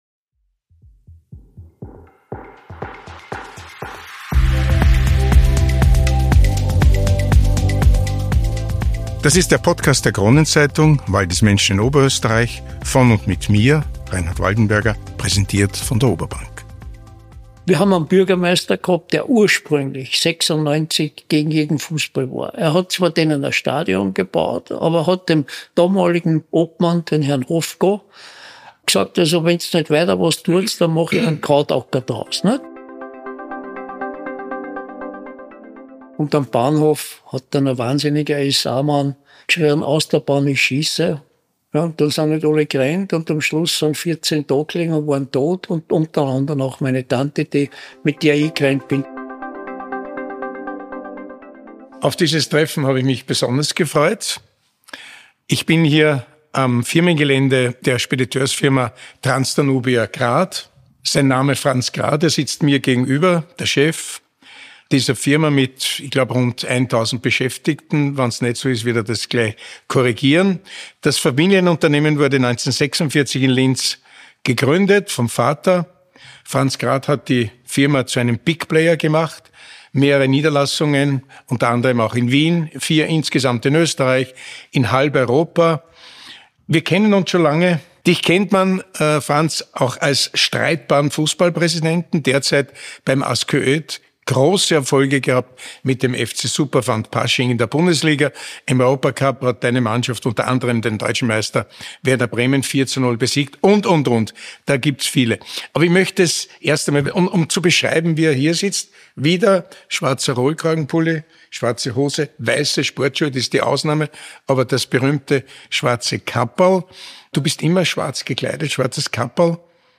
Ein offenes, bewegendes und streitbares Gespräch über Überleben